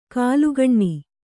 ♪ kālugaṇṇi